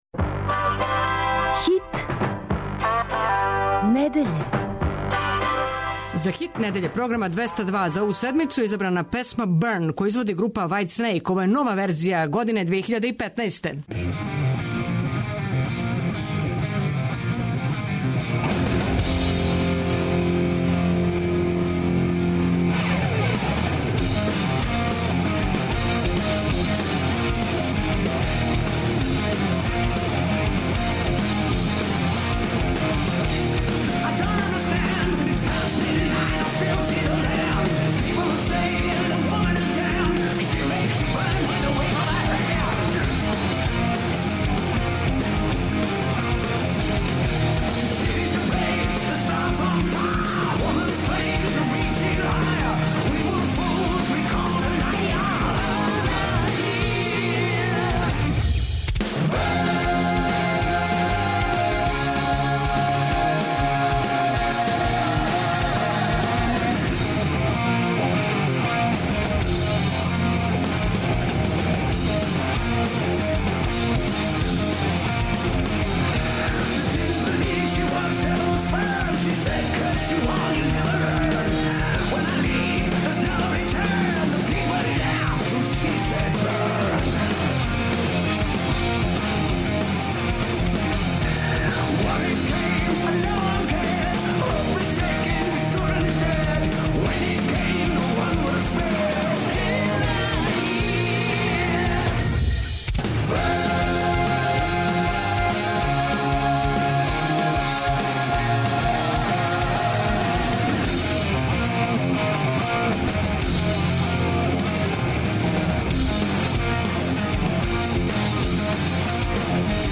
У данашњој емисији - Flashback - слушамо старе хитове 202-ке на данашњи дан.